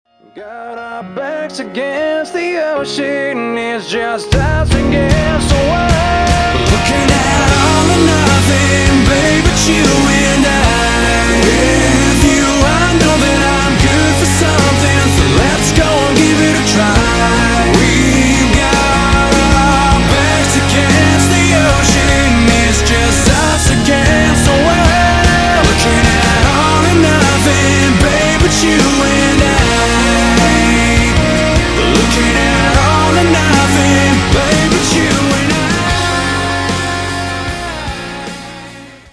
• Качество: 192, Stereo
мужской вокал
мелодичные
спокойные
Alternative Rock
post-grunge
рок-баллада